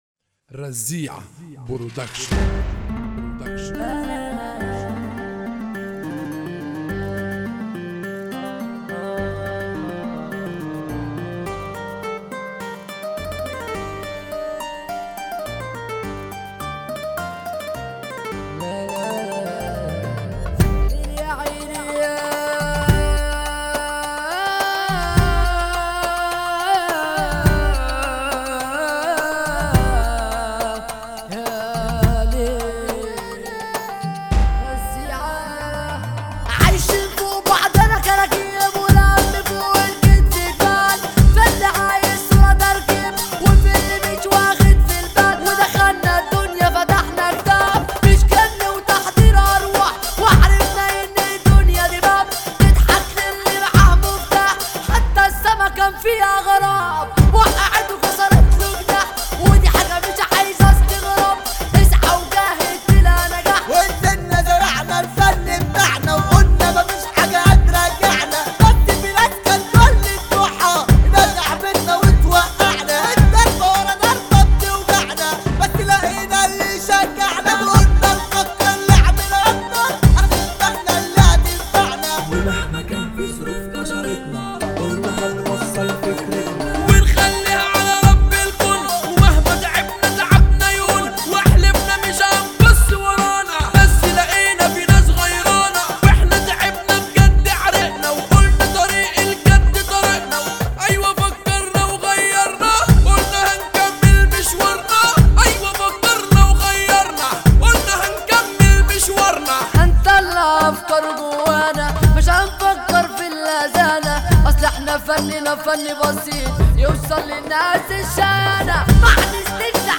MahrGan